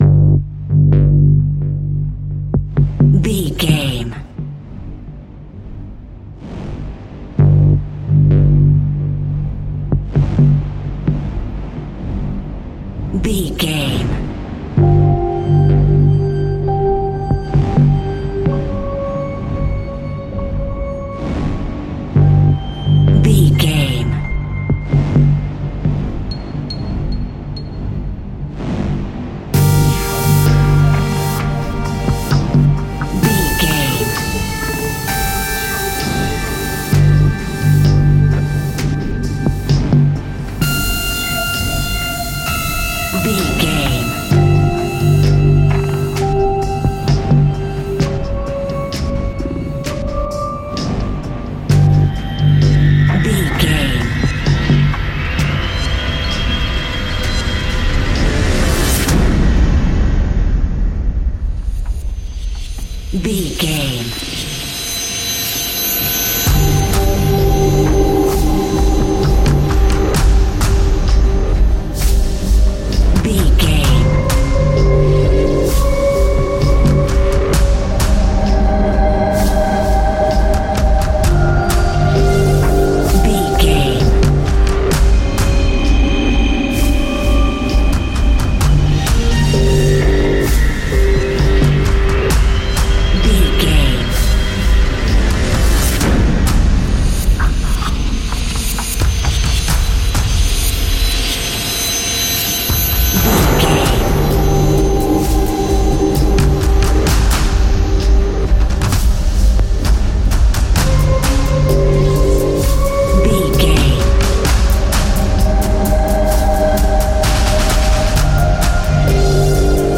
An aggressive and dark piece of electronic industrial music.